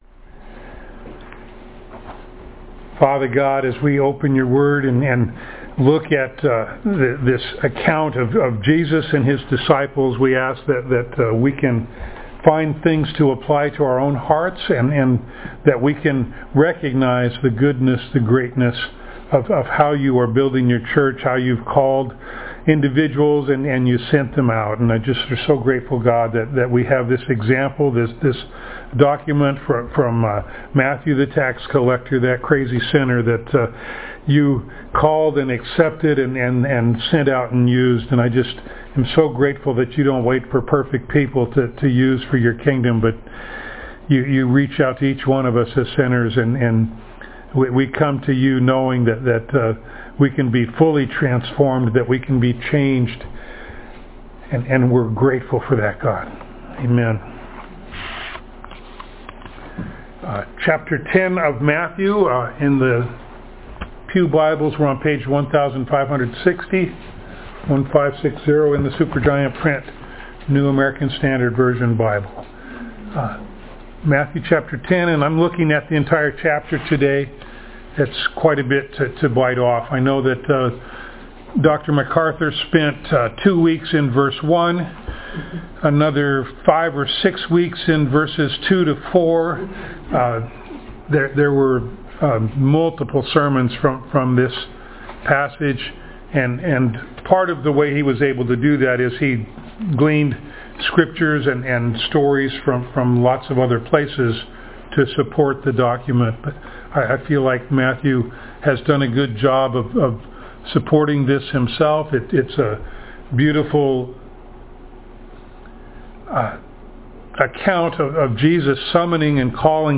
Matthew Passage: Matthew 10:1-42 Service Type: Sunday Morning Download Files Notes « The Miracle of Forgiveness Doubt